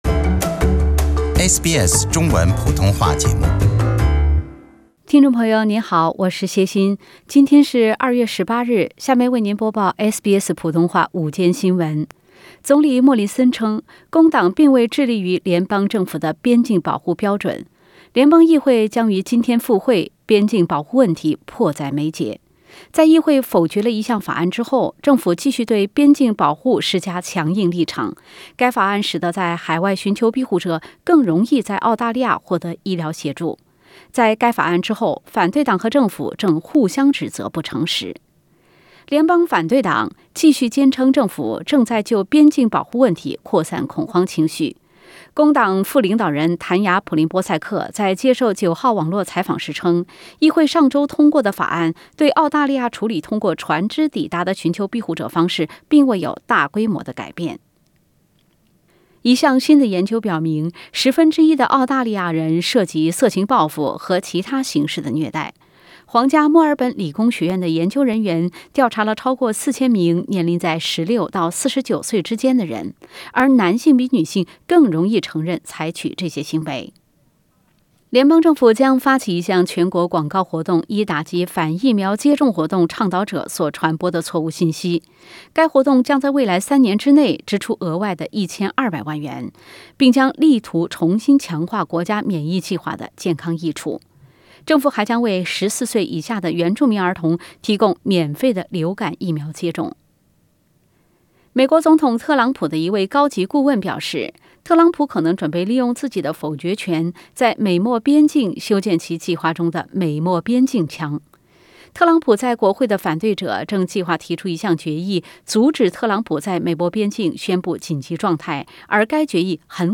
SBS 午间新闻 （02月18日）